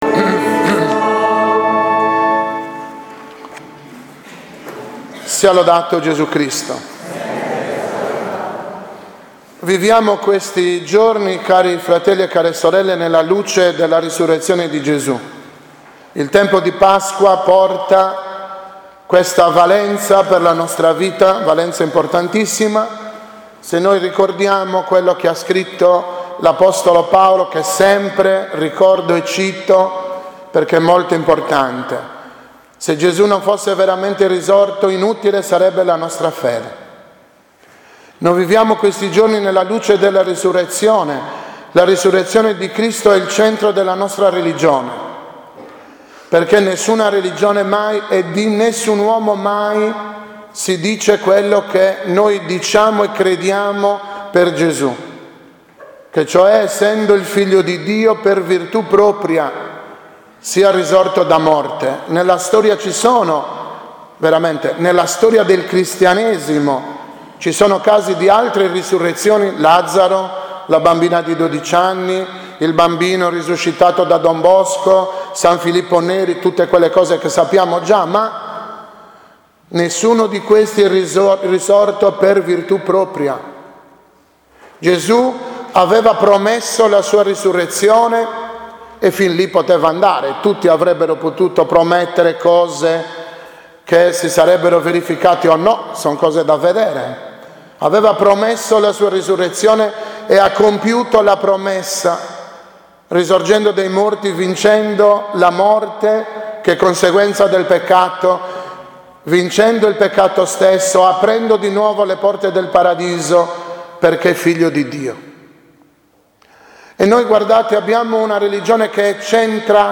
15.4.2018 OMELIA DELLA III DOMENICA DI PASQUA